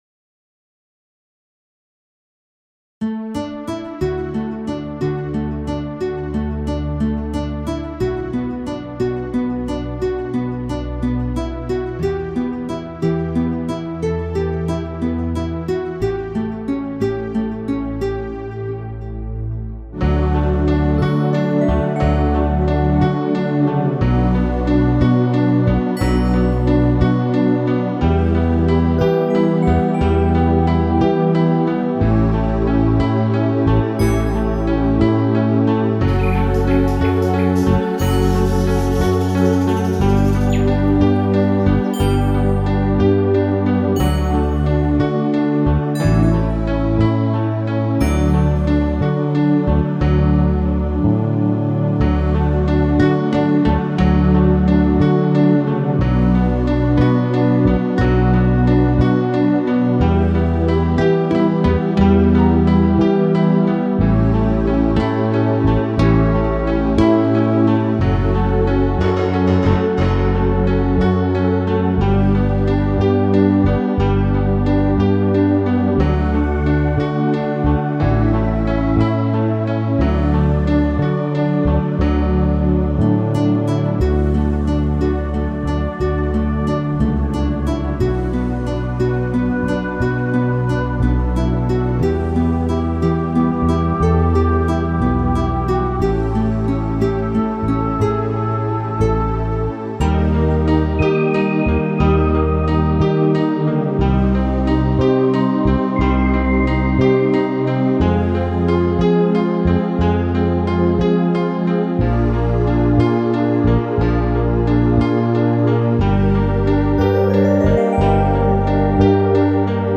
mp3 (минус)